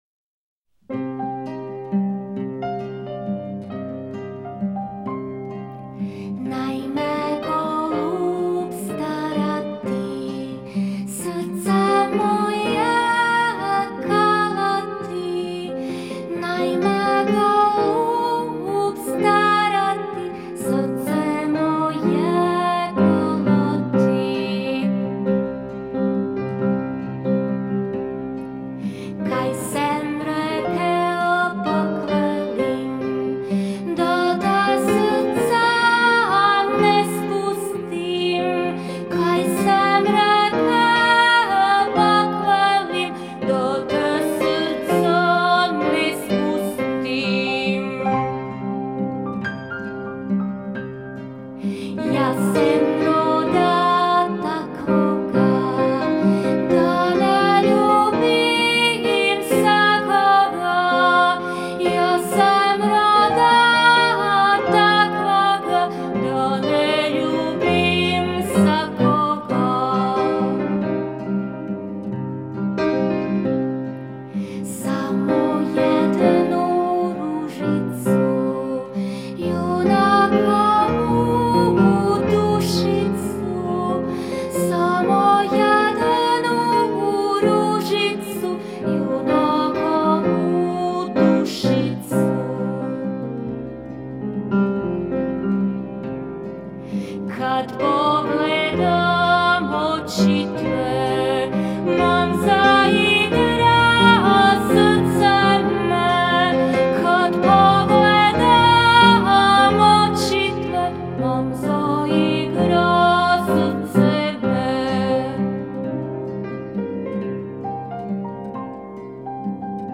glas i gitara
glasovir i udaraljke
cimbale